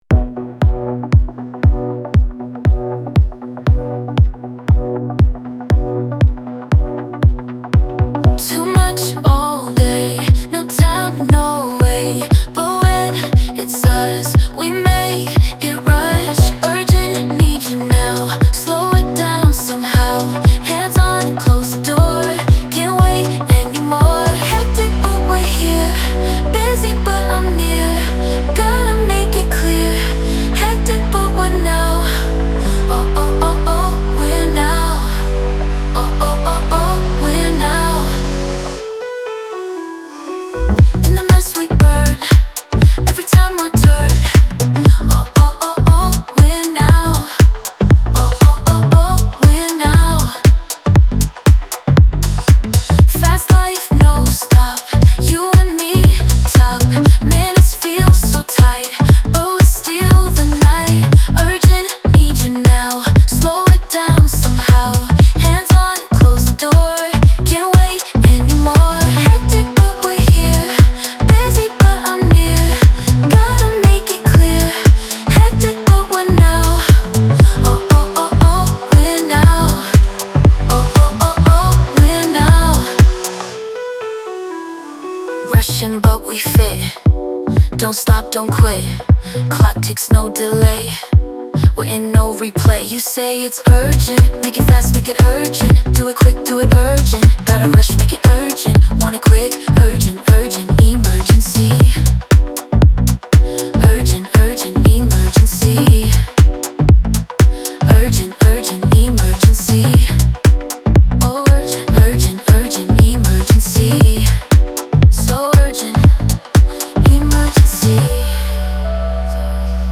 CLUB HOUSE - SUPERB SONG
infectious